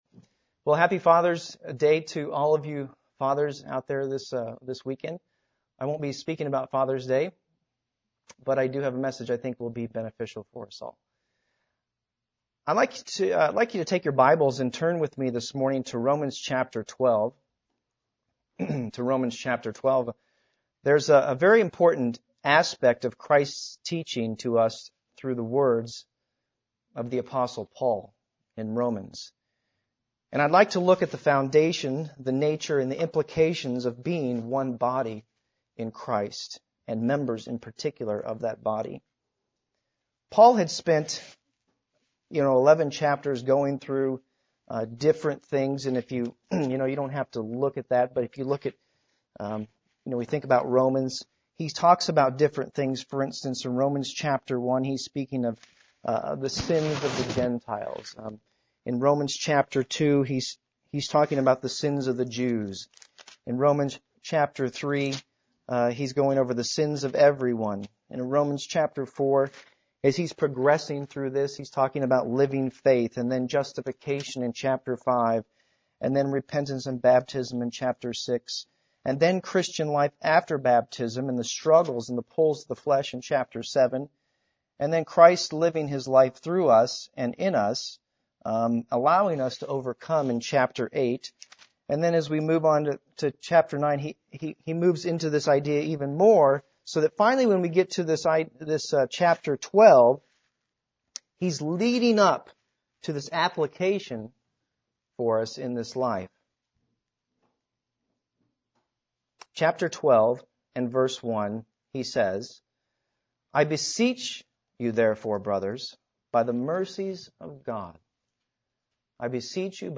Given in Columbia - Fulton, MO
UCG Sermon Studying the bible?